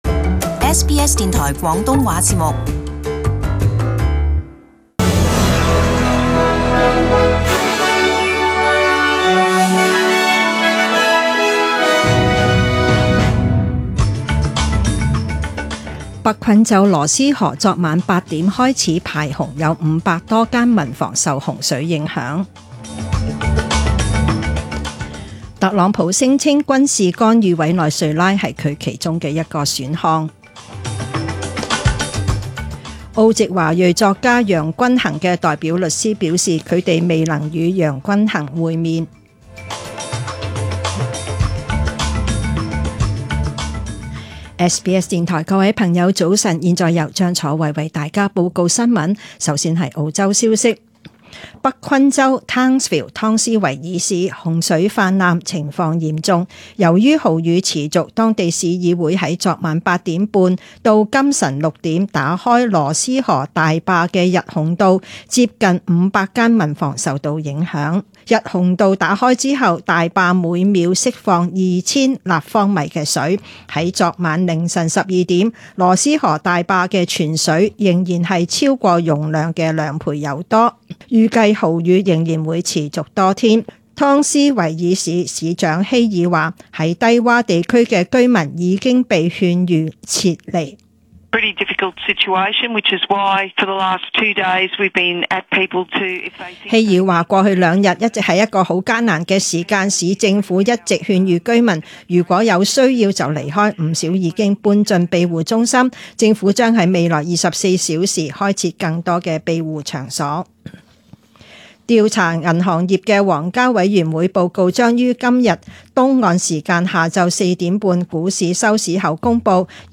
Detailed morning news bulletin.